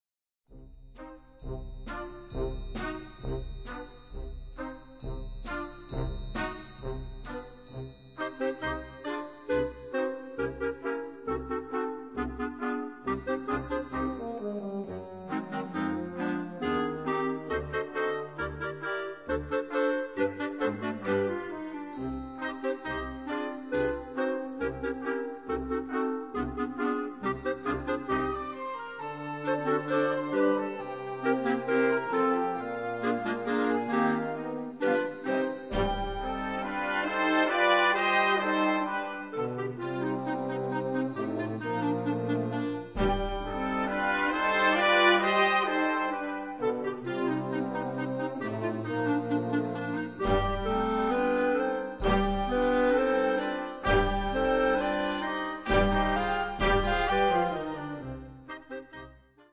Gattung: Solostück für 4 (oder mehr) B-Klarinetten
Besetzung: Blasorchester